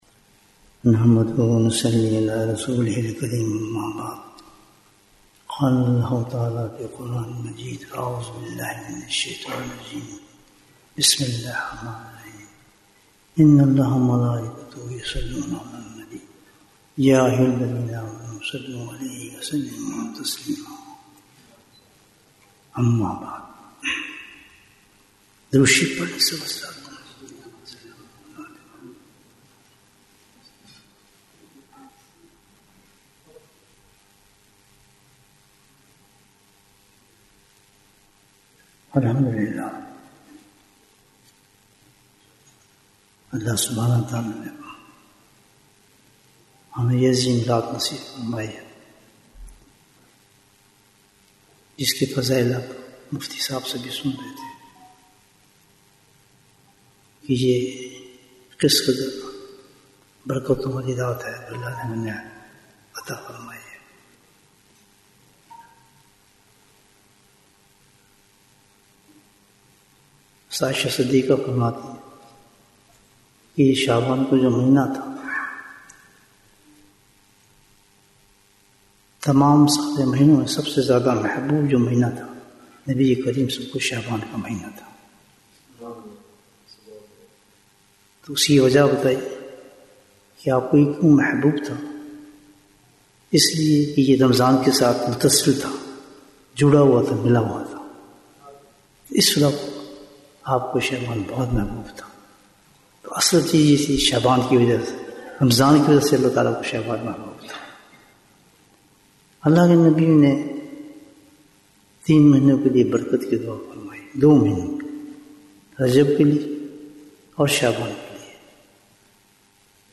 Bayan, 57 minutes 13th February, 2025 Click for English Download Audio Comments How do we Celebrate Shab-e-Baraat?